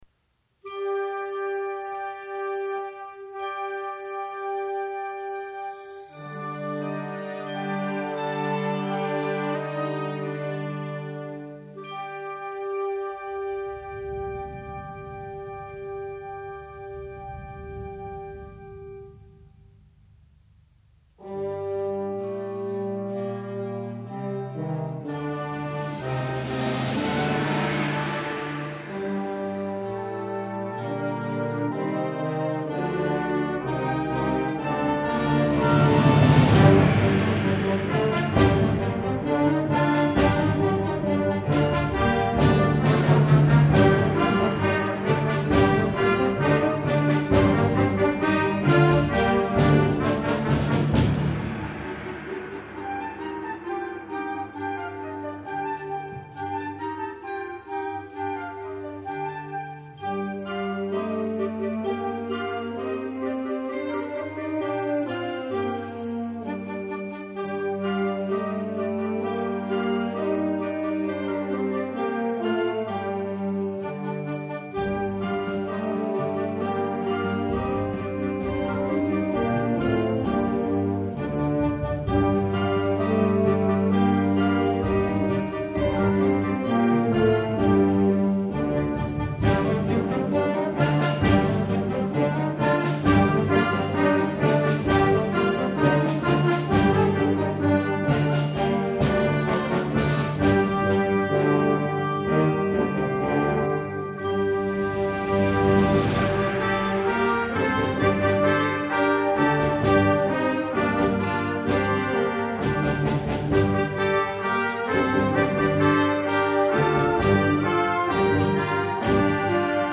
Genre: Band
Flute
Bb Clarinet
Alto Saxophone
Trumpet
Trombone
Tuba
Xylophone
Percussion 1 (wind chimes, bass drum, snare drum, timbales)
Percussion 2 (triangle, tam-tam)
Timpani